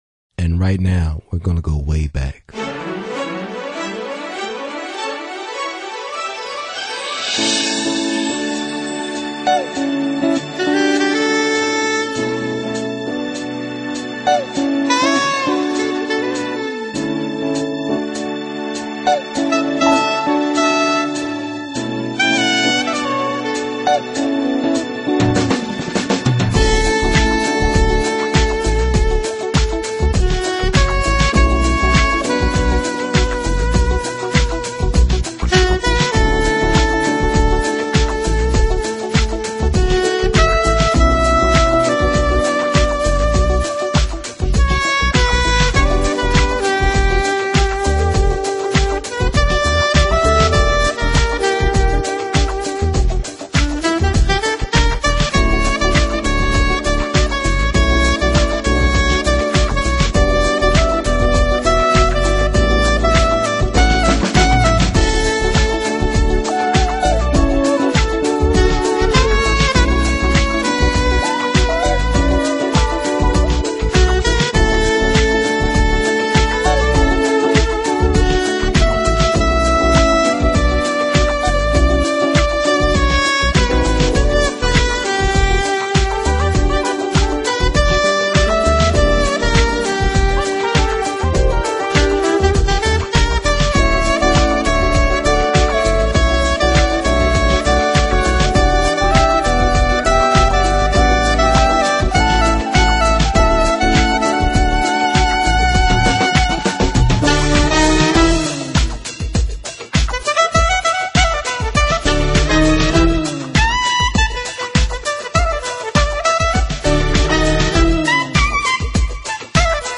音乐类型：Jazz 爵士
音乐风格：Smooth Jazz